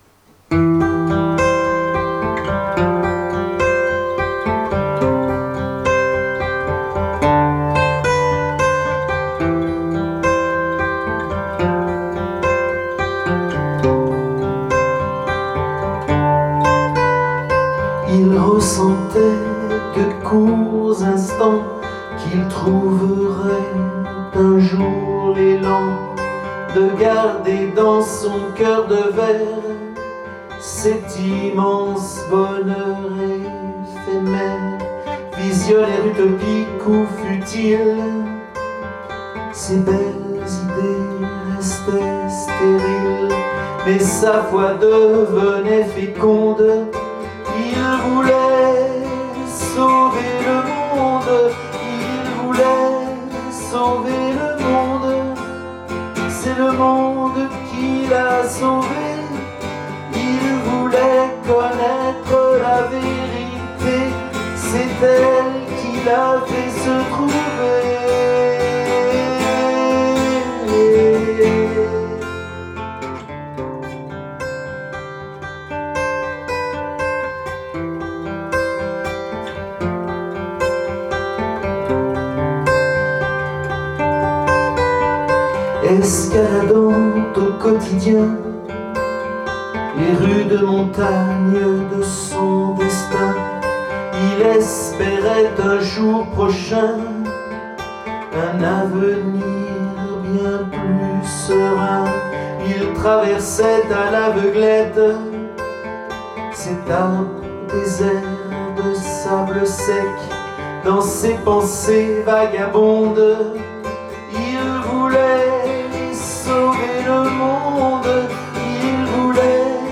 Une rumba philosophique où l’e danseur découvre que le monde détient les clés de son salut.